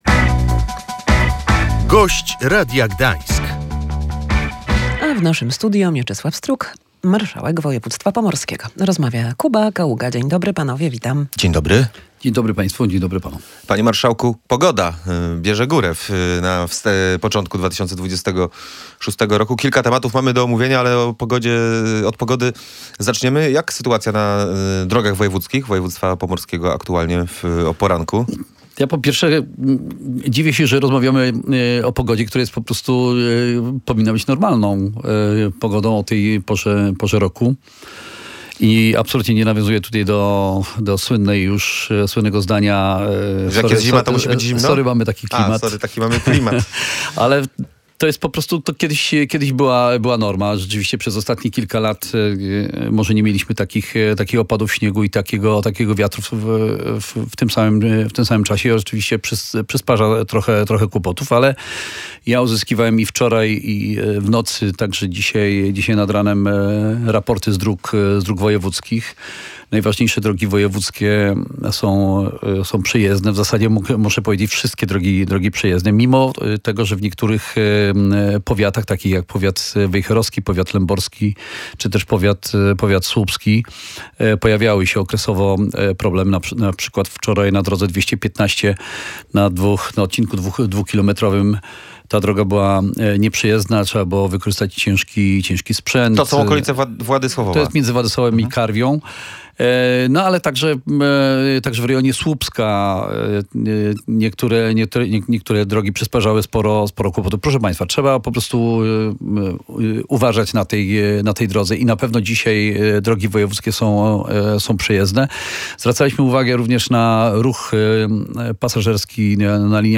Wszystkie główne i dojazdowe drogi na Pomorzu są przejezdne – mówił w Radiu Gdańsk marszałek województwa Mieczysław Struk.